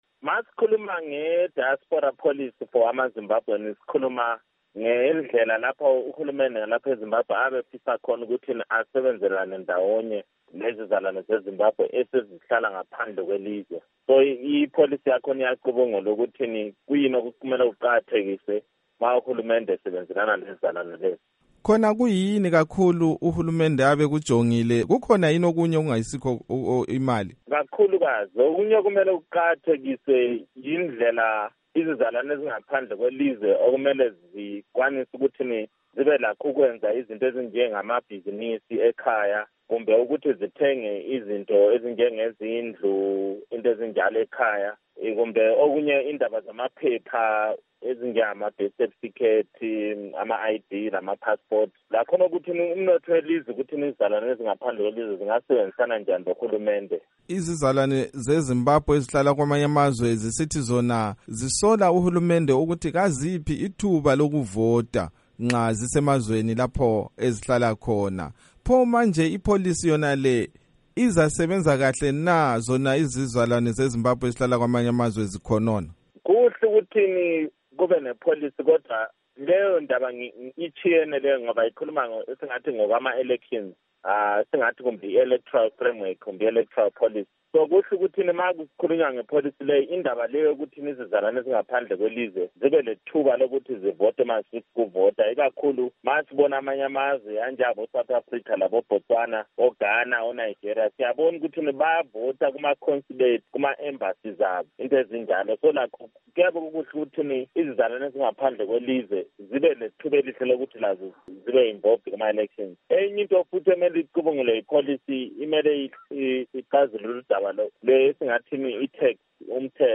Ingxoxo loMnu. Daniel Molokele